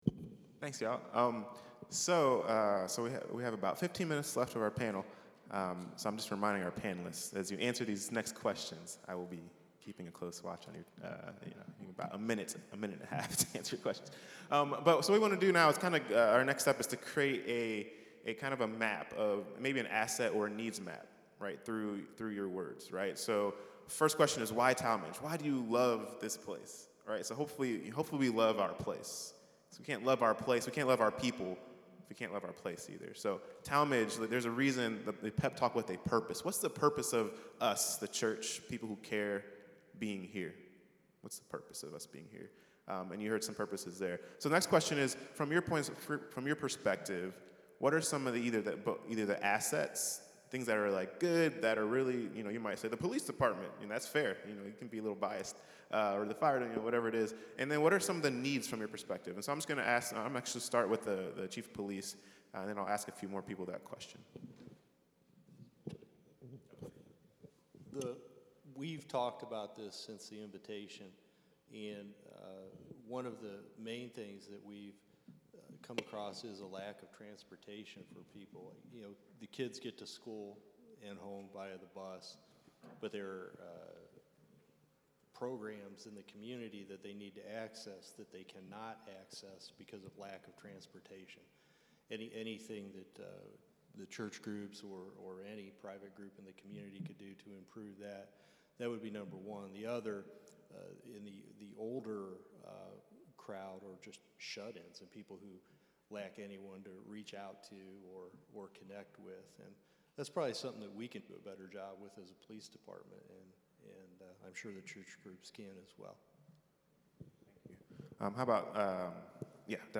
Panel Discussion – Meeting the Needs of the Tallmadge Community (Part 2)